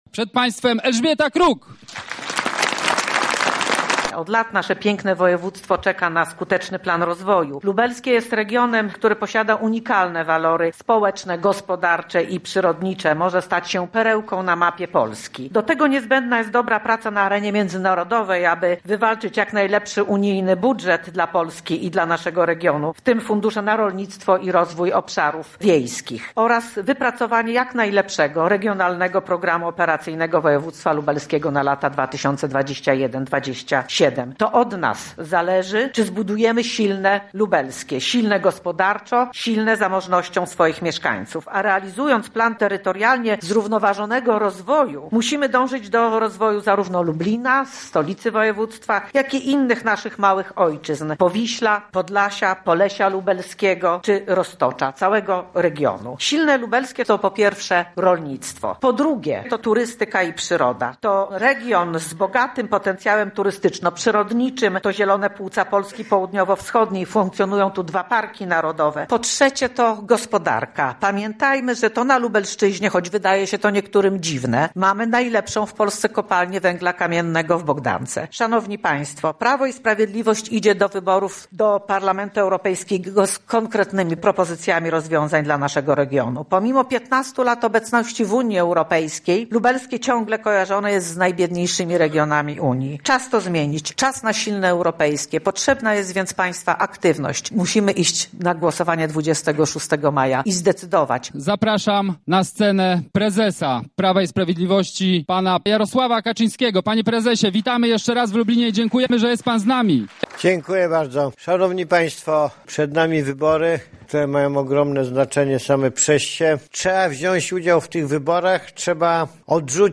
Spotkanie w Lublinie rozpoczęło się o godz. 14.30 w Lubelskim Centrum Konferencyjnym przy ul. Grottgera 2.
Relacja